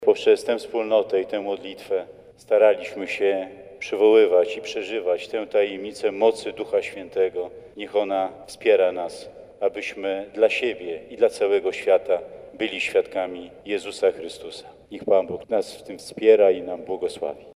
W prawosławnej katedrze świętej Marii Magdaleny na warszawskiej Pradze odbyło się w poniedziałek nabożeństwo ekumeniczne.
Z kolei biskup pomocniczy diecezji warszawsko-praskiej bp Marek Solarczyk zwrócił uwagę, że jedność jest darem Ducha Świętego.